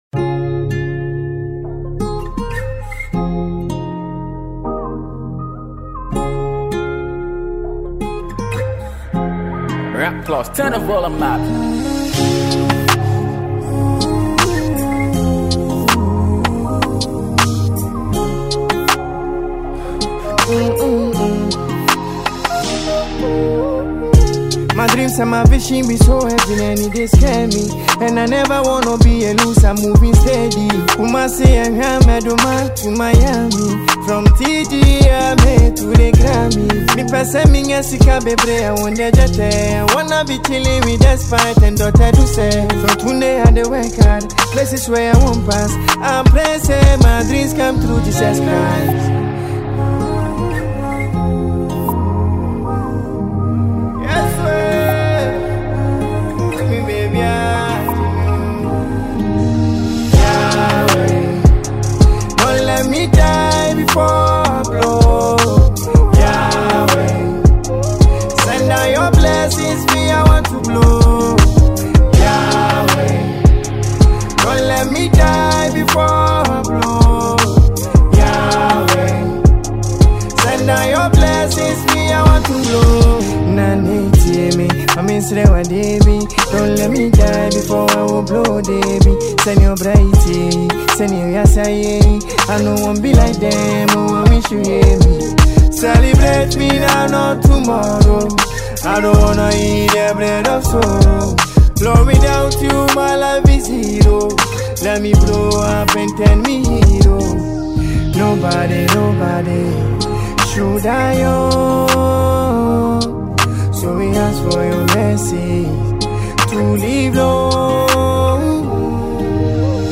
a Ghanaian musician and afrobeat singer
melodic voice smoothly blend on the afrobeat